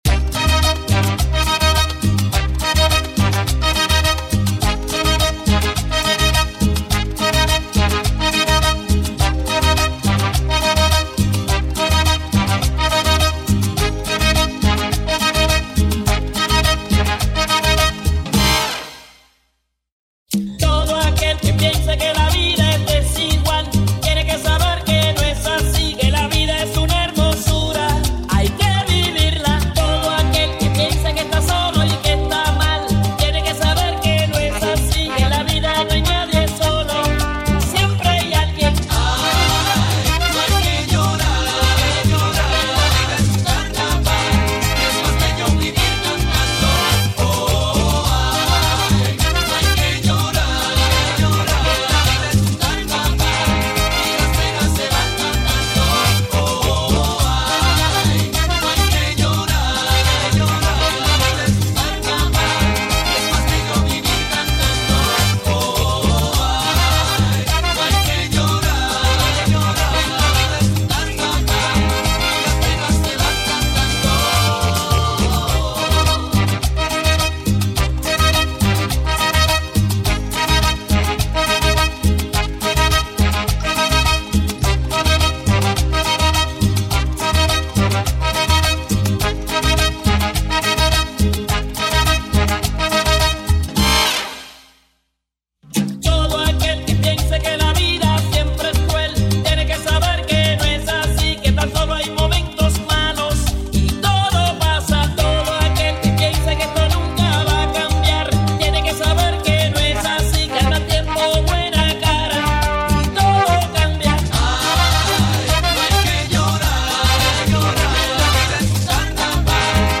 Turn Up the Heat with Miami’s Ultimate Salsa Vibes